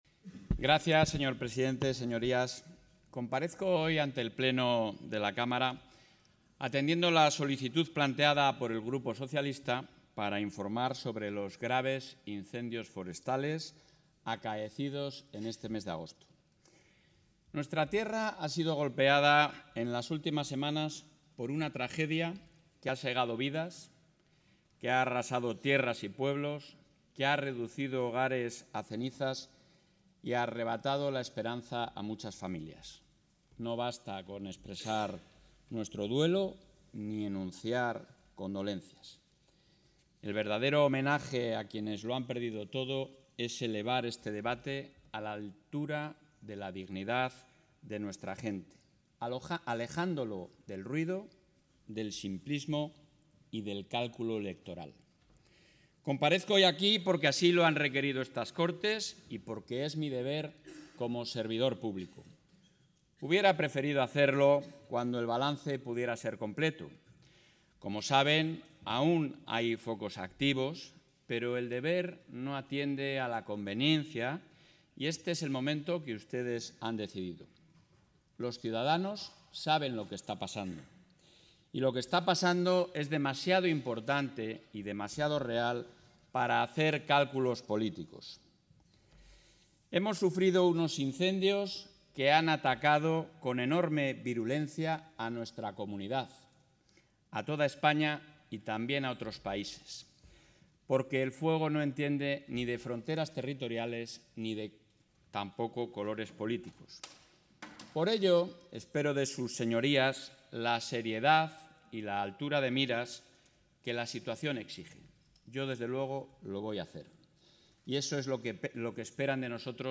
El presidente de la Junta de Castilla y León, Alfonso Fernández Mañueco, ha comparecido hoy en las Cortes autonómicas...